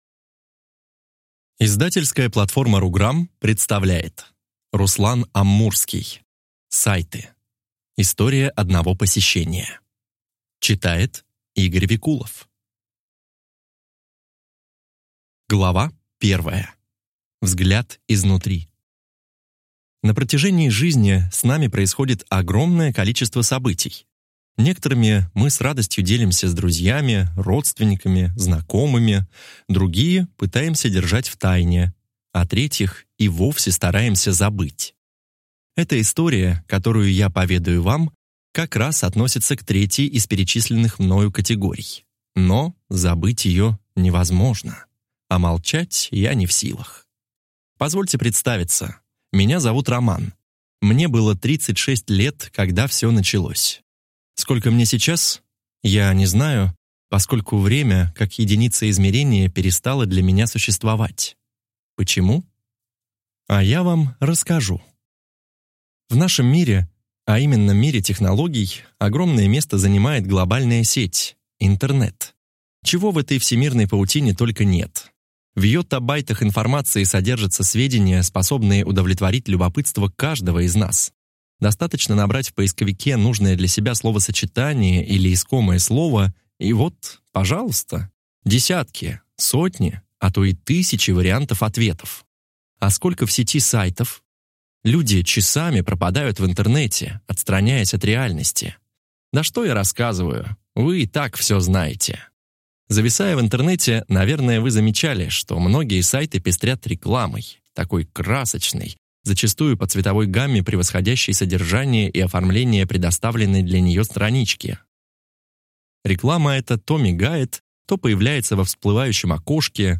Аудиокнига Сайты. История одного посещения | Библиотека аудиокниг